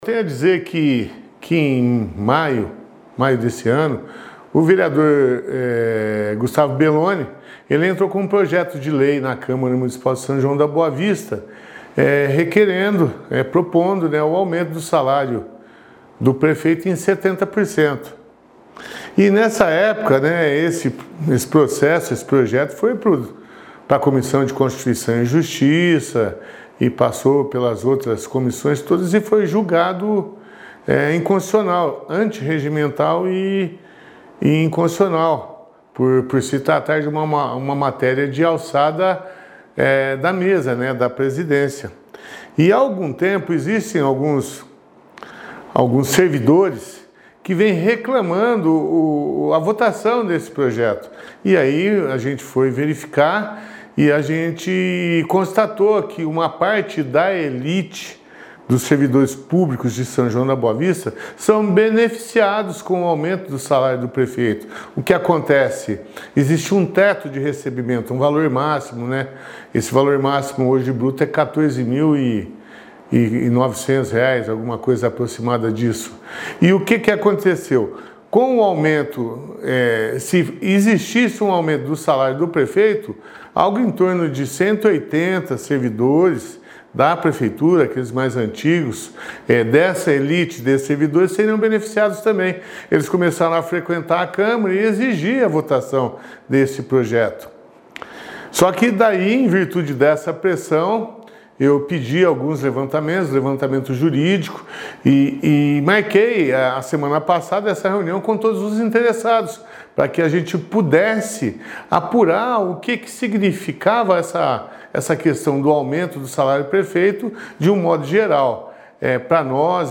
Conversamos com Carlos Gomes, que explicou o motivo do adiamento da votação. Ele exigiu que a resposta em áudio fosse transmitida na íntegra, e assim foi feito, com o conteúdo dividido em duas partes.